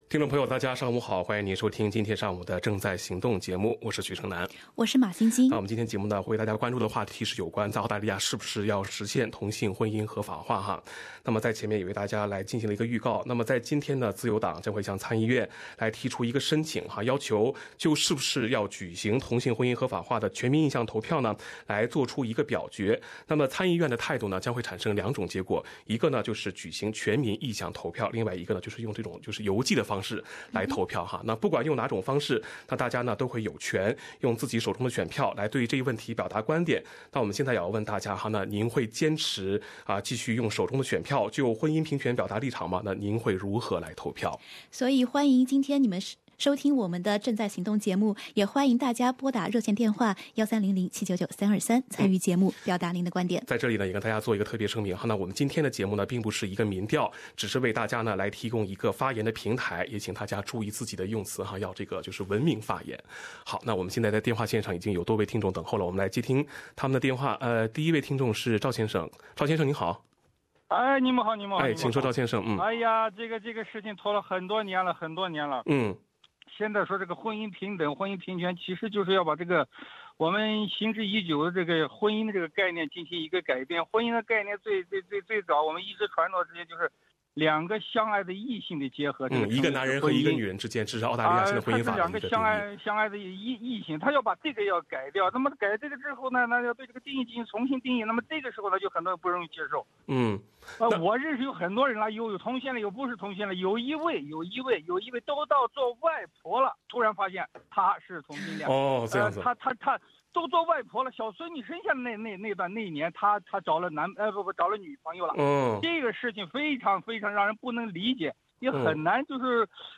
请收听在参议院投票结果出炉之前，今天早上的直播节目中听众在此话题上的讨论。